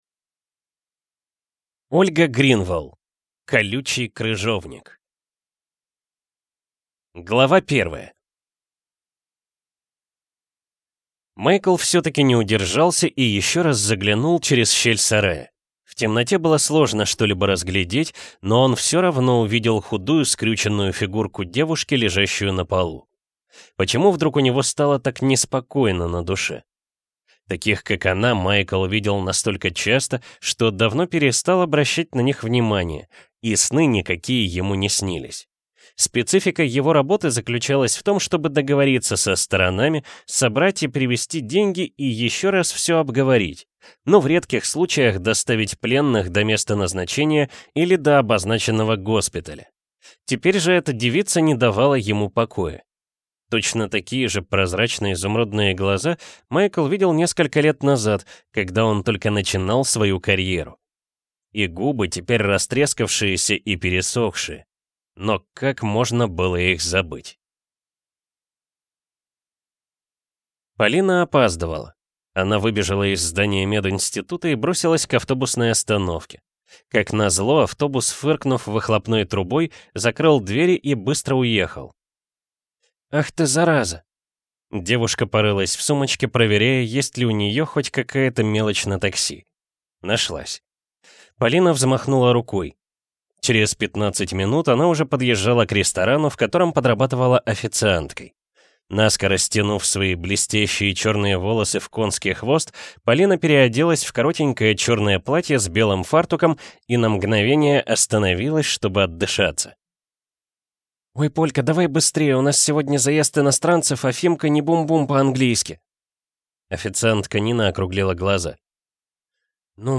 Аудиокнига Колючий крыжовник | Библиотека аудиокниг